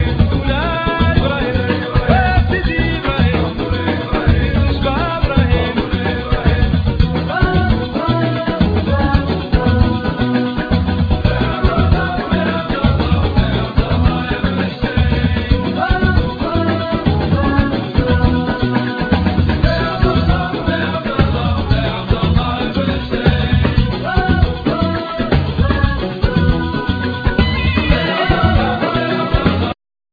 Lead Vocal,Drums,Percussion,Gumbri
Banjo,Mandola,Background vocals
Soprano & Tenor Saxophone